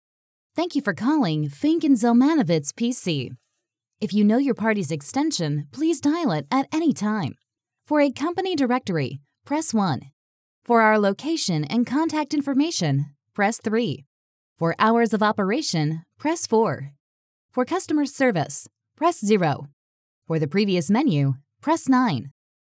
Auto Attendant
Female